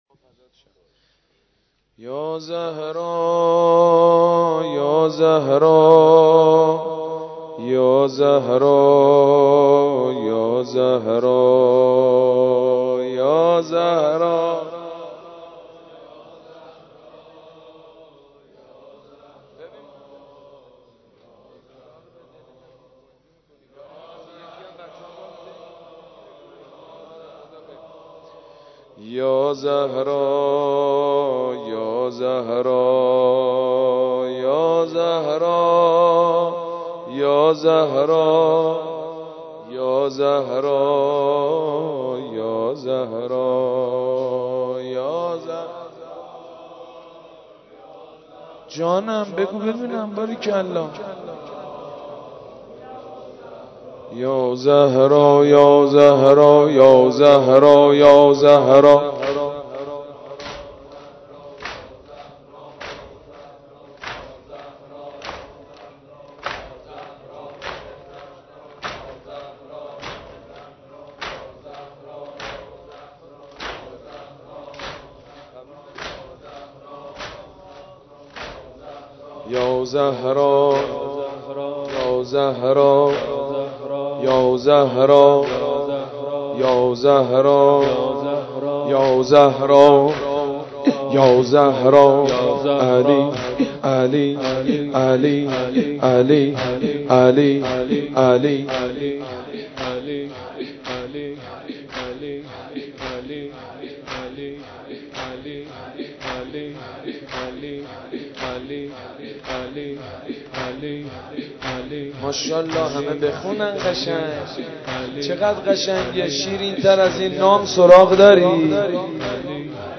مداحی فاطمیه بنی فاطمه | یک نت